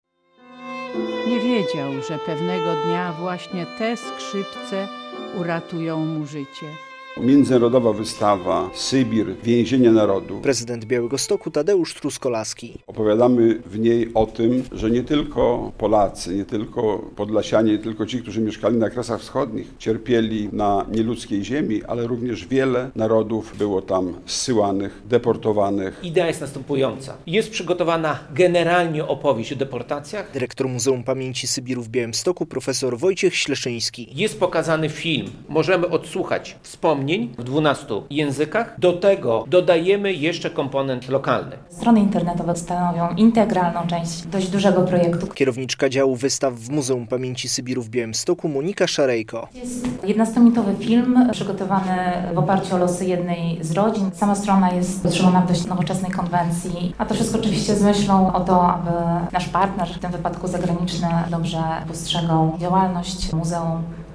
Najnowszą propozycją muzeum - którą przedstawiono w czwartek (16.10) na konferencji prasowej - jest międzynarodowa wystawa "Sybir - więzienie narodów" przygotowana w kilkunastu wersjach językowych.